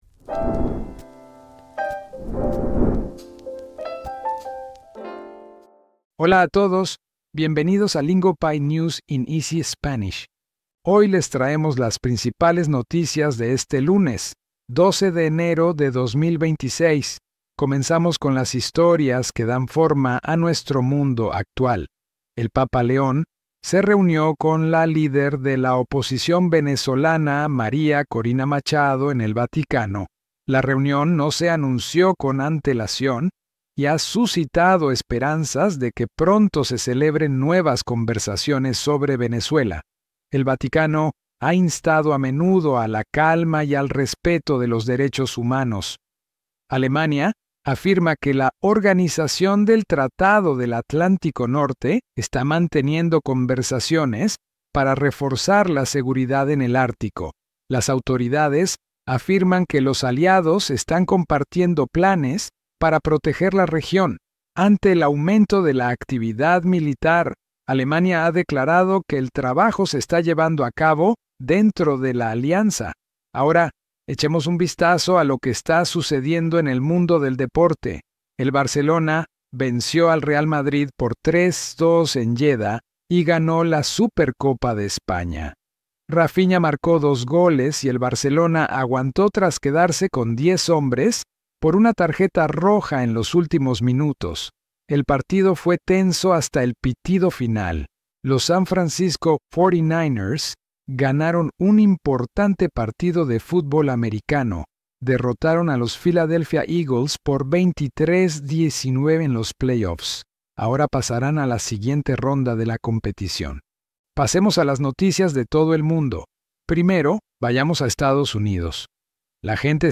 Each episode presents real global headlines in natural, learner-friendly Spanish.